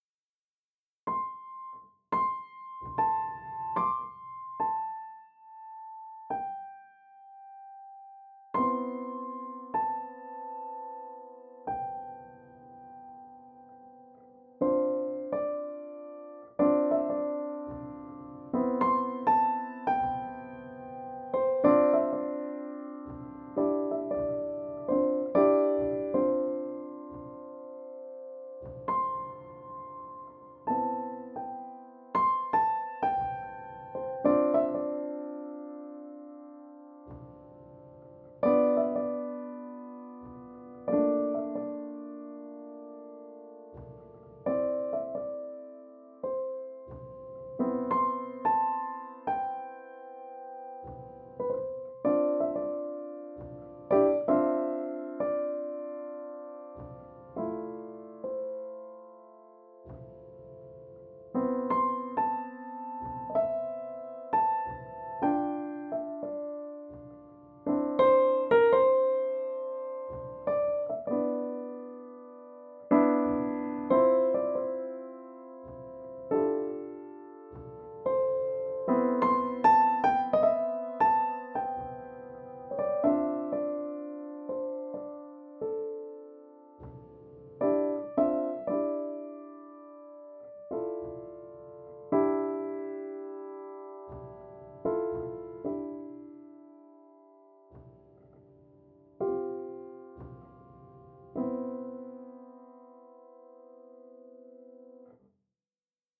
Hindsight - A New Piano Composition